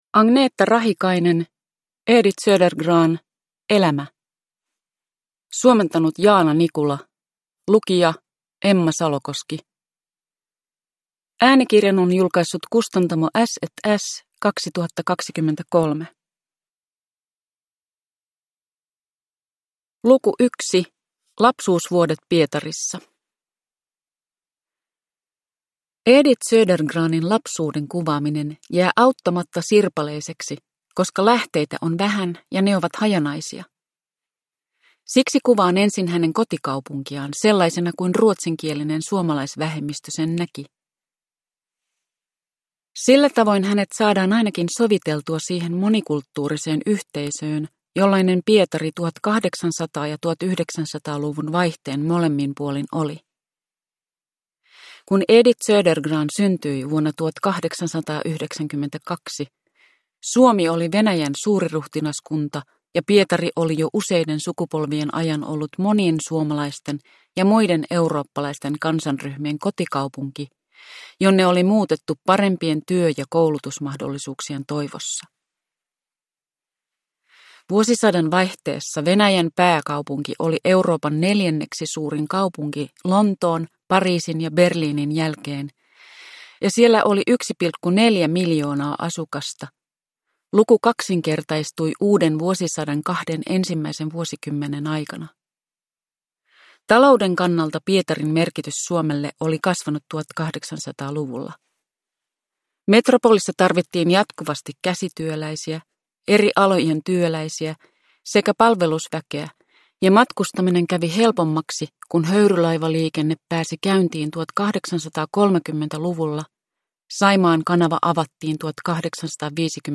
Edith Södergran - Elämä – Ljudbok
Uppläsare: Emma Salokoski